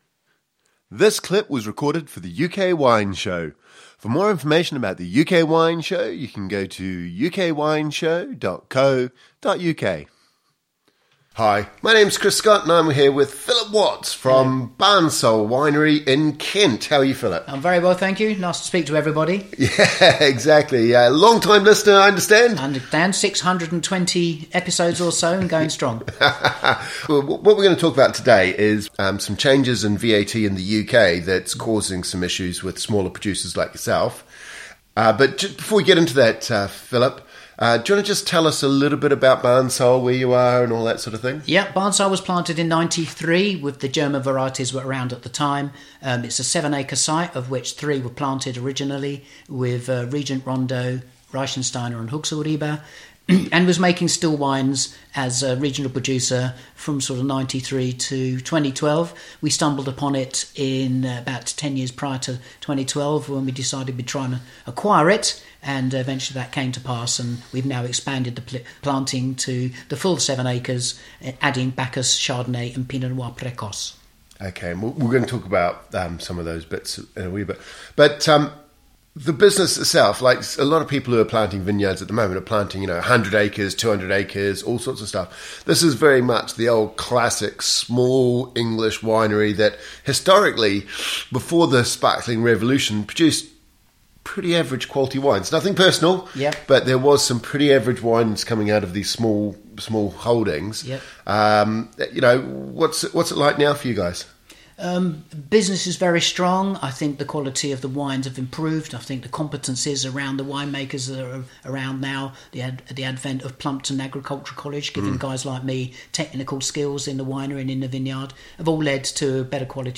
In this first interview we discuss how the tax changes post Brexit have negatively affected direct to consumer sales.